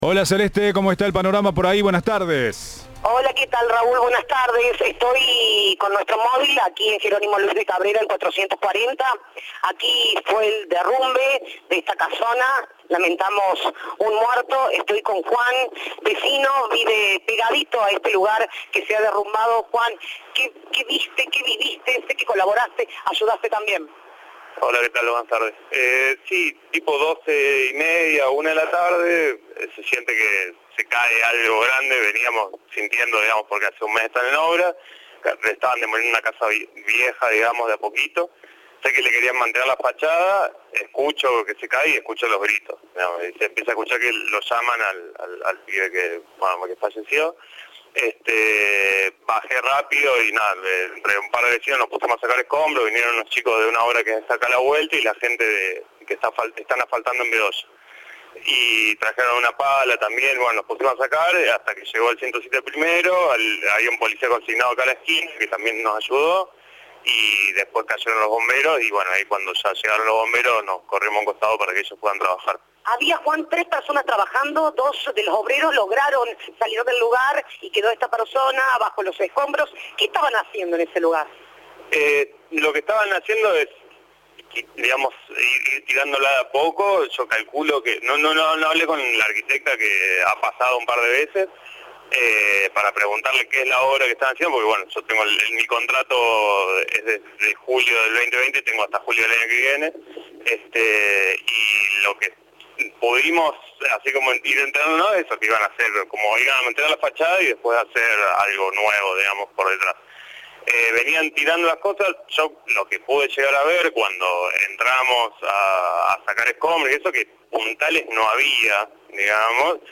Testimonio de un vecino que ayudó tras el derrumbe en la casona de Alta Córdoba.